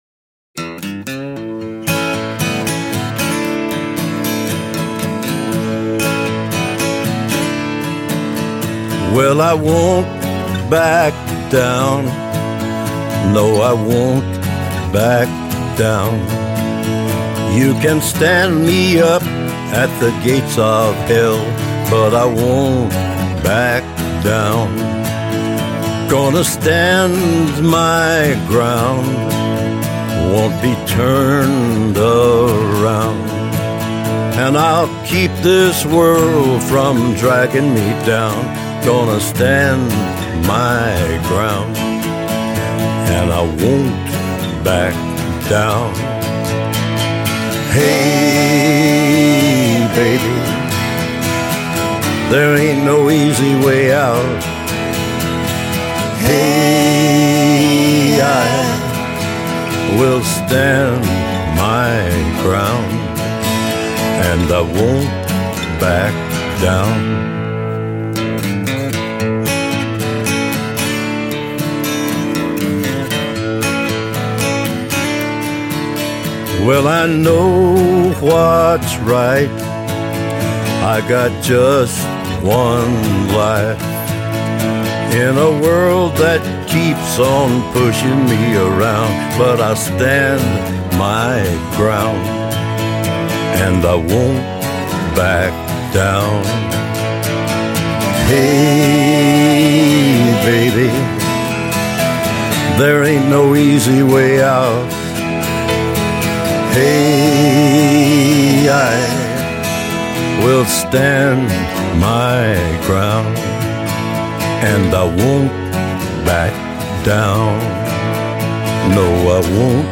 country کانتری